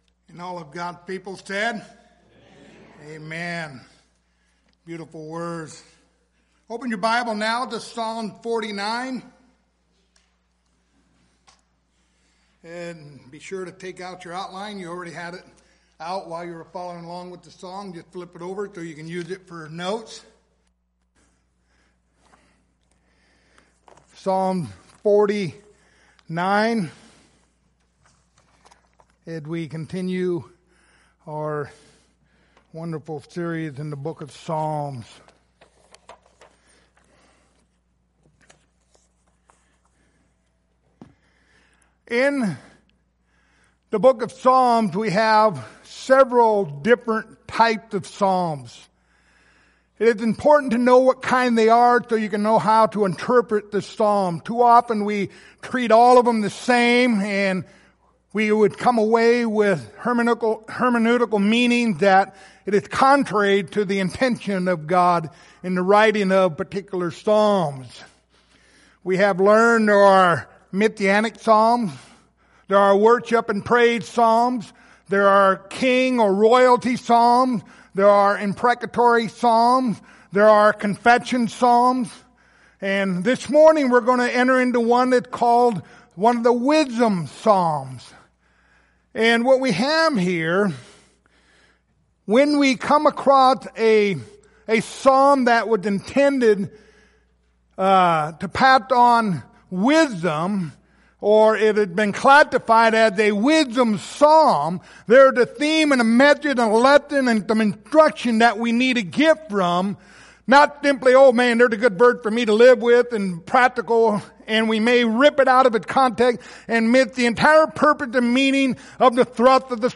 The book of Psalms Passage: Psalm 49:1-20 Service Type: Sunday Morning « A Child is Born